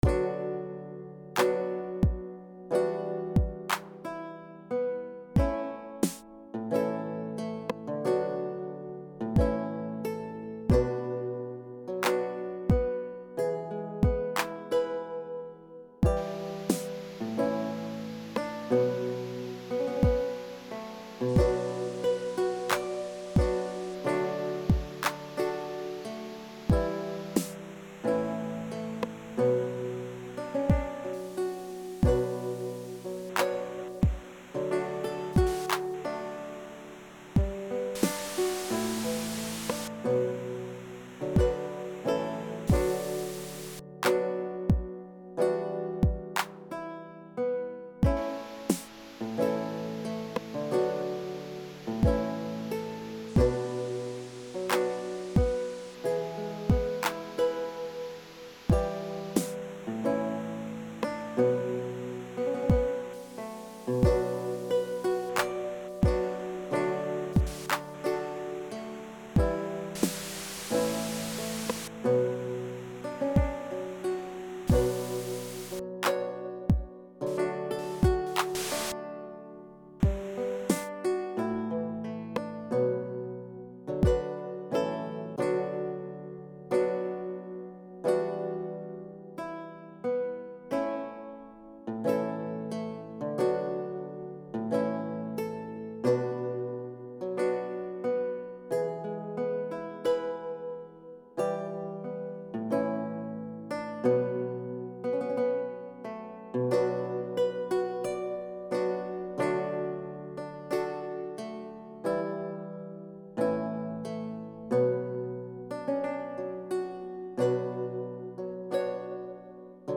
90stringsharpnoise
90stringsharpnoise.mp3